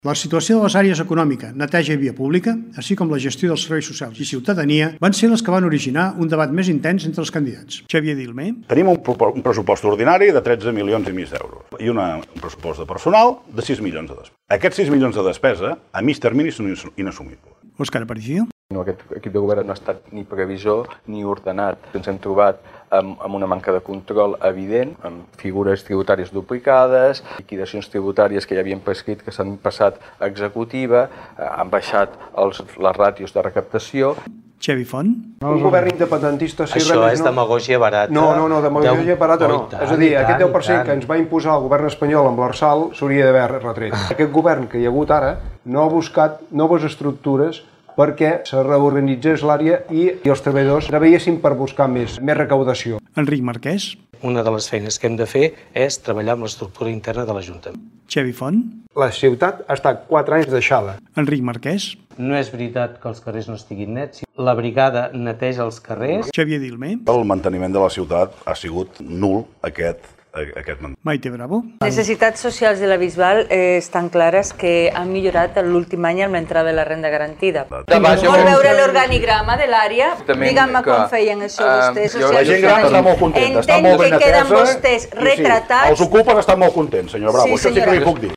Debat Electoral la Bisbal 2019
Com no podia ser d’altra manera Ràdio Capital ha emès el col·loqui.
La situació de les àrees econòmica, neteja i via pública, així com la gestió de serveis socials i ciutadania van ser les que van originar un debat més intens entre els candidats.